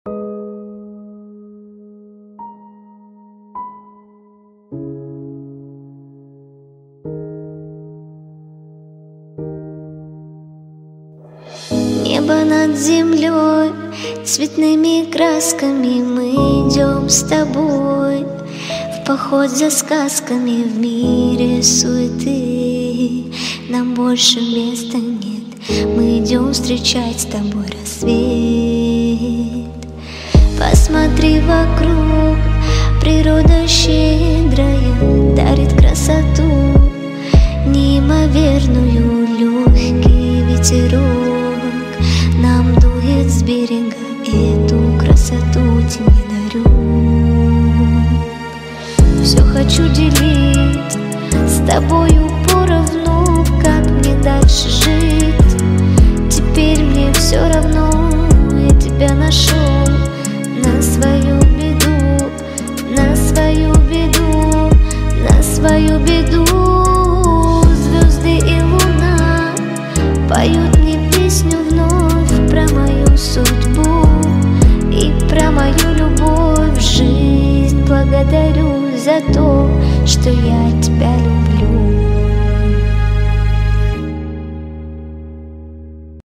женская версия
поёт девушка